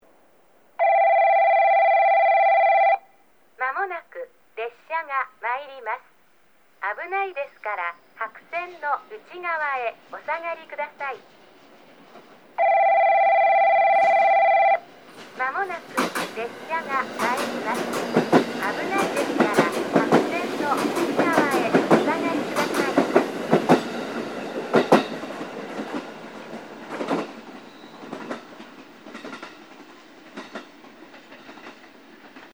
ホームは時間が止まったかのように静まり返っており、鳥の声と付近を走る車の音が小さく聞こえるだけです。
2番のりば接近放送　女声